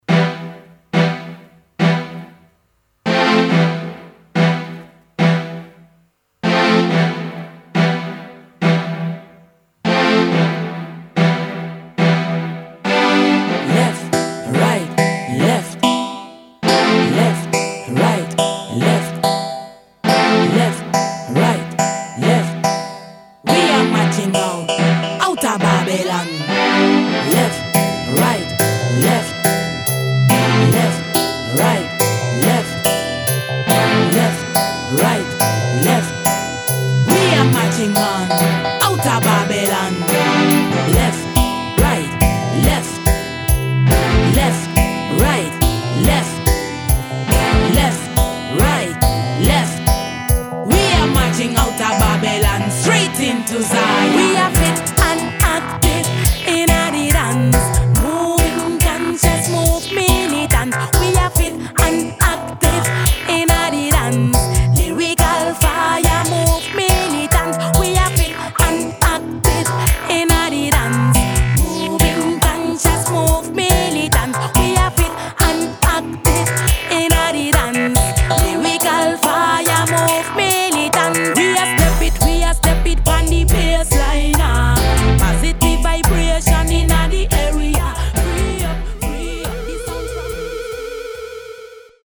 One Digi Stepper
Heavy & Tuff Riddim with some crucial vocals
Side A presents the tuff Steppers version
All recorded, mixed and mastered